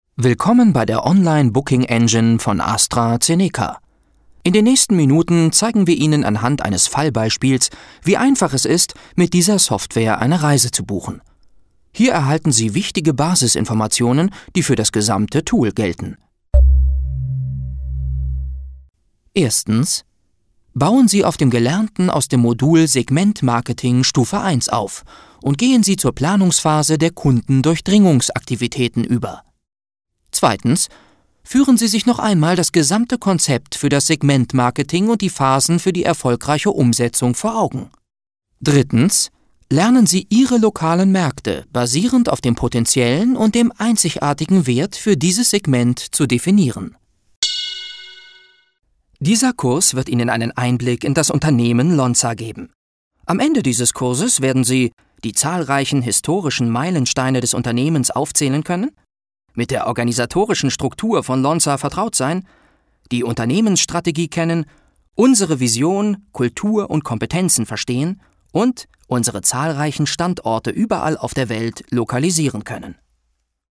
Die junge Stimme mit Erfahrung.
Junge Stimme, Sprecher für: Werbung, Audio Ads, Dokumentation, E-Learning, Zeichentrick, Jingles, Lieder, Synchron, etc.
Sprechprobe: Sonstiges (Muttersprache):